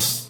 OpenHH2.wav